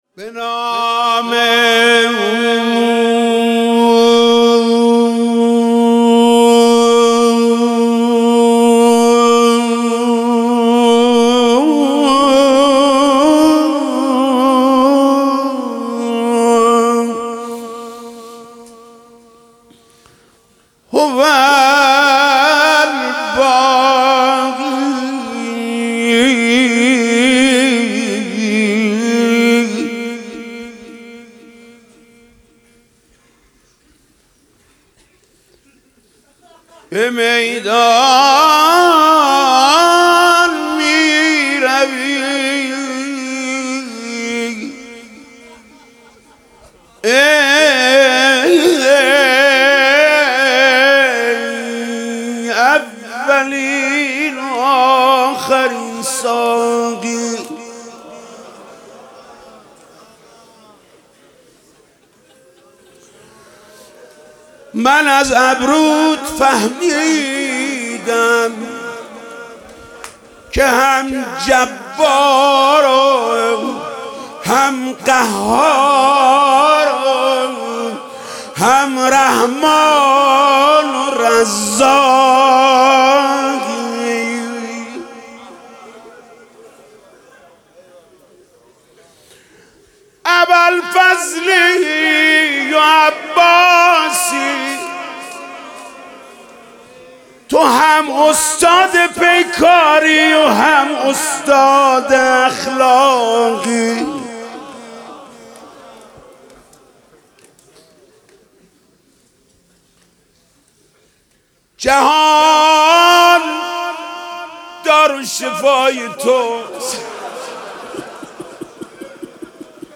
محرم96 - روضه - به نام او هوالباقى